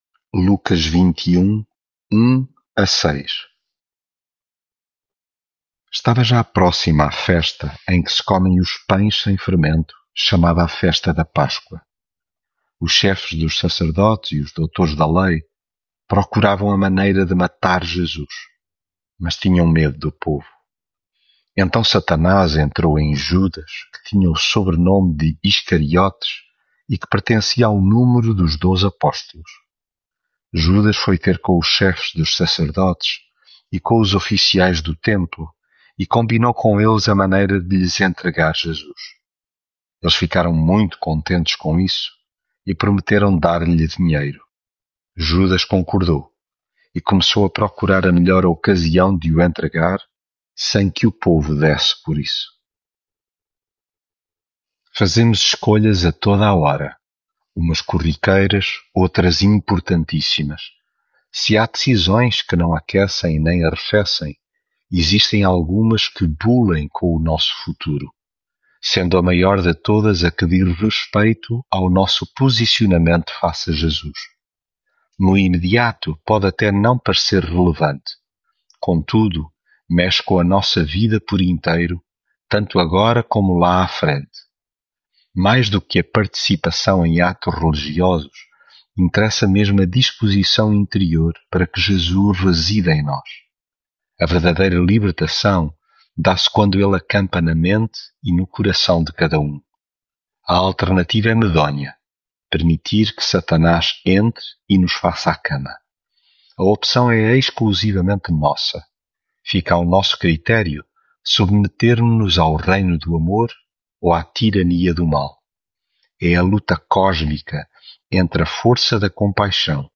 Devocional
leitura bíblica